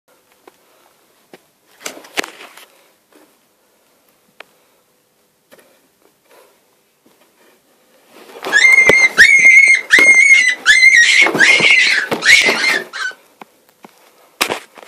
Визг дикого кролика в ночи